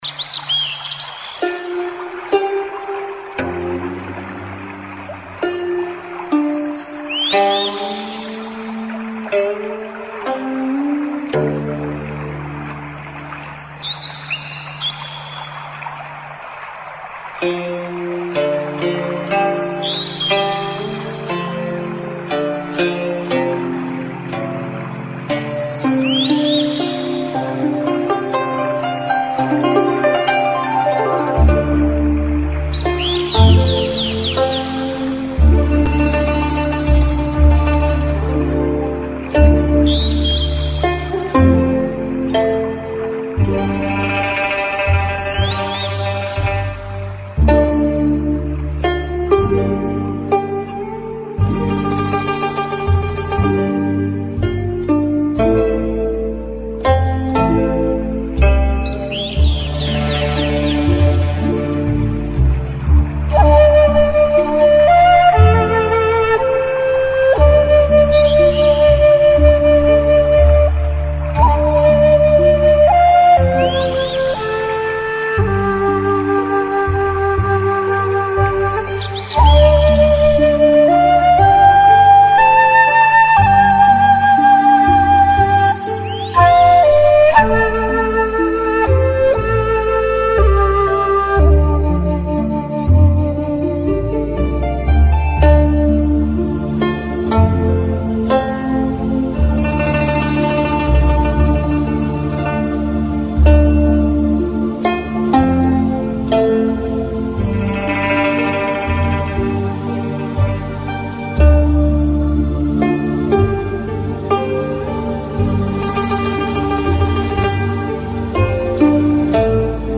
Chinese Music